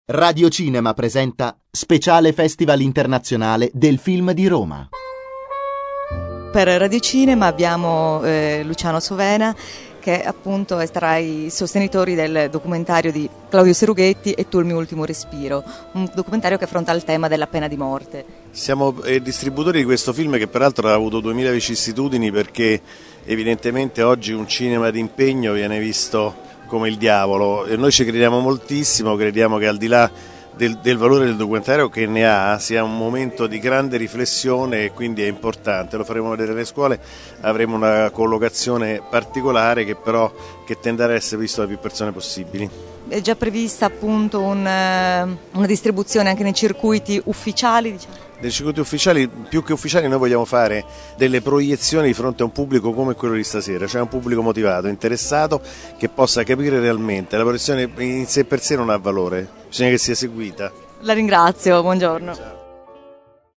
Presentato fuori concorso nella sezione Alice nella città al 5° Festival Internazionale del Film di Roma, E’ tuo il mio ultimo respiro? sarà distribuito da Cinecittà Luce.